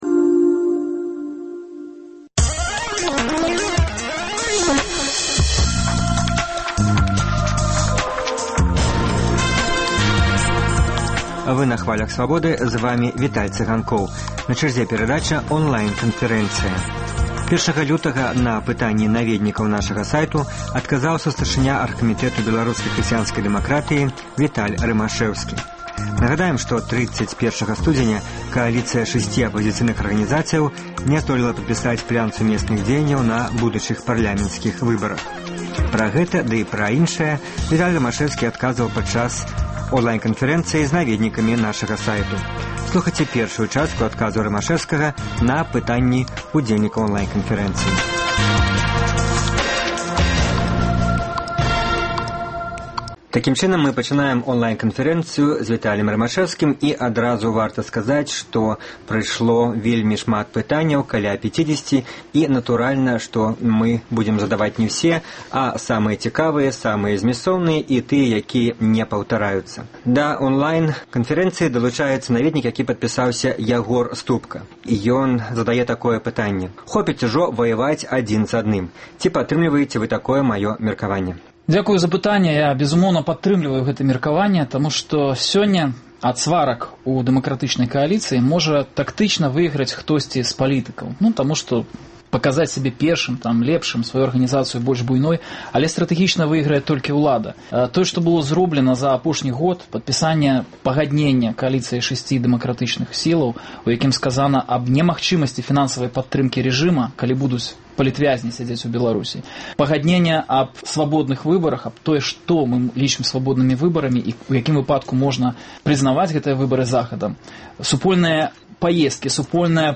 Радыёварыянт онлайн-канфэрэнцыі